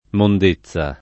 mond%ZZa] s. f. (lett. «pulizia»; region. «immondizia») — dal sign. proprio di «purezza, l’esser mondo», attraverso il concetto del «far pulizia», il sign. antifrastico d’uso region. (come in nettezza) di «spazzatura, immondizia» (e questo, anche nella forma roman. di monnezza o nella napol. di munnezza)